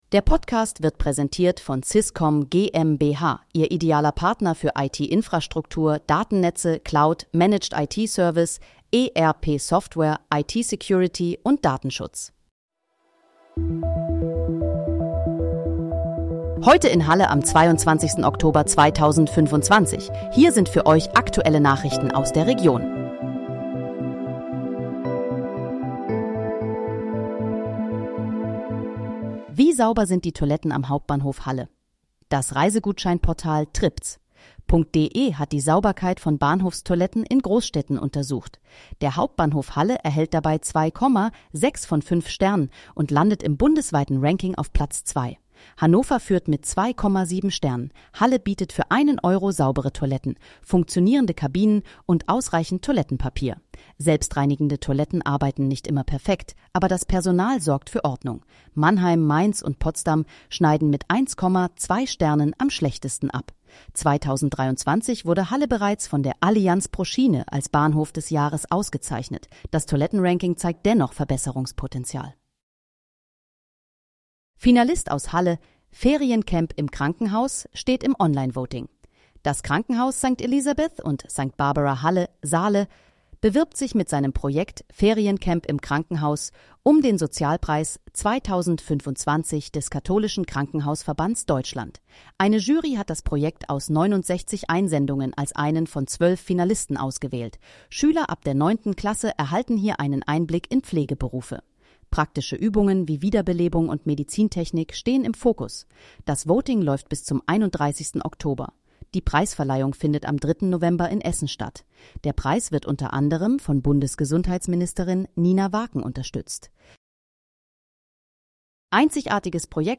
Heute in, Halle: Aktuelle Nachrichten vom 22.10.2025, erstellt mit KI-Unterstützung
Nachrichten